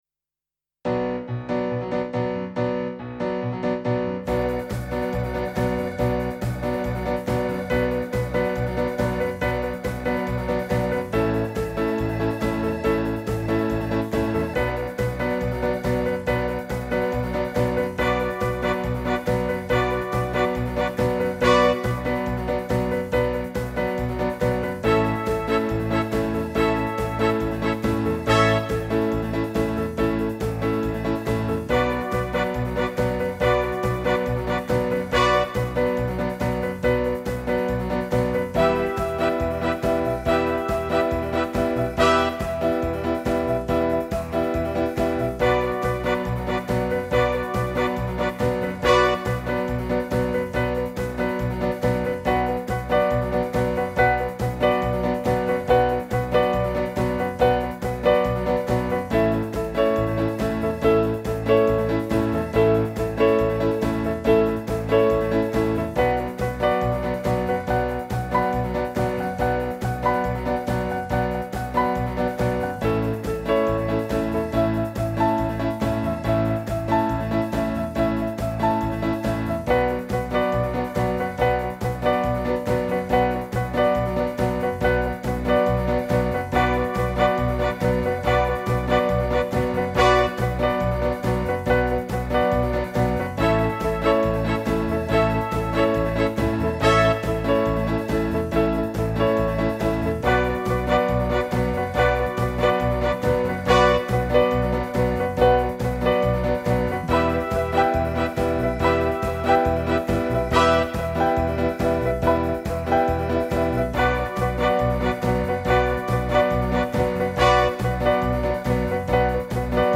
Home > Music > Rnb > Bright > Running > Chasing